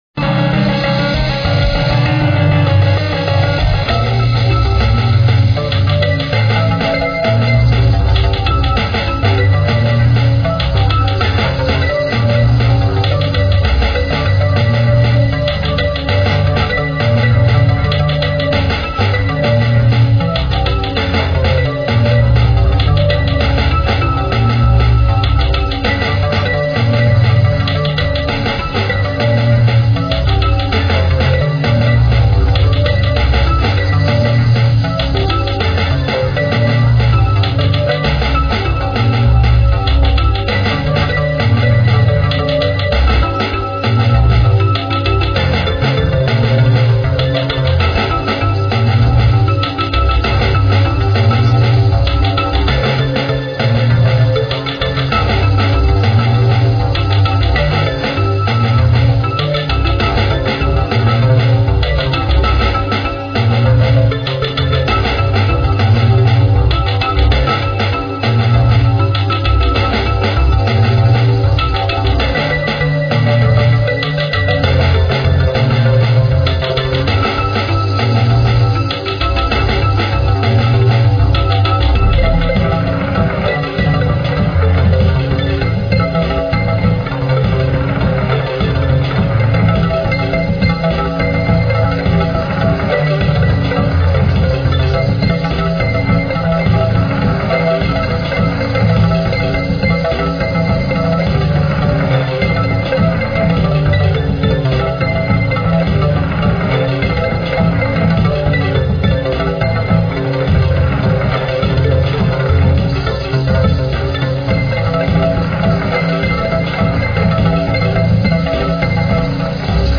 Royalty Free Music for use in any type of
An upbeat track with a thumping intro and offbeat
bridge plus tempo changes.